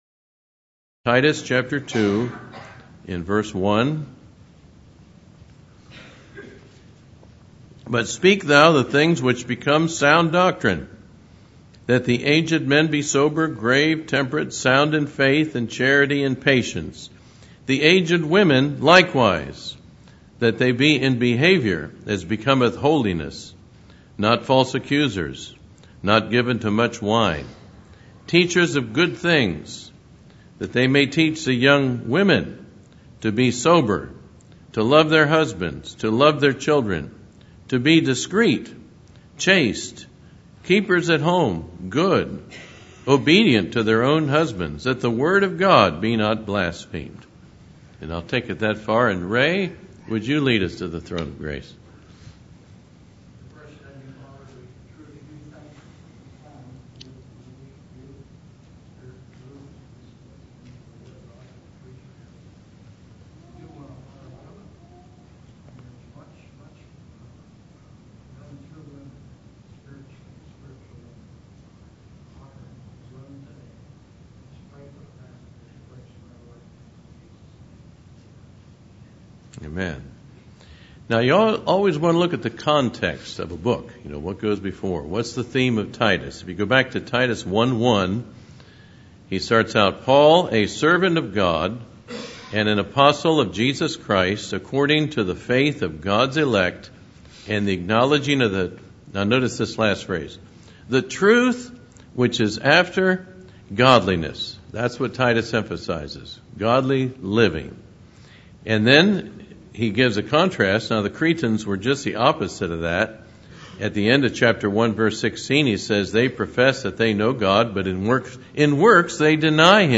Titus 2:3-5 Service Type: Morning Worship Topics